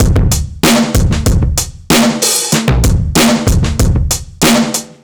Index of /musicradar/analogue-circuit-samples/95bpm/Drums n Perc
AC_OldDrumsA_95-98.wav